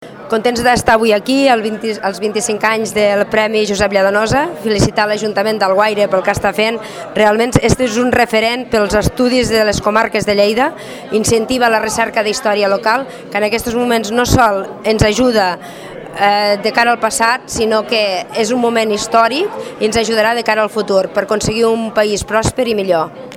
Aquest diumenge al migdia s’ha fet públic el nom del guanyador en l’acte institucional realitzat a Alguaire i que ha comptat amb la presència, entre les autoritats, de la vicepresidenta de l’IEI, Rosa Pujol, qui ha refermat el compromís de la Diputació amb la història i la cultura del territori.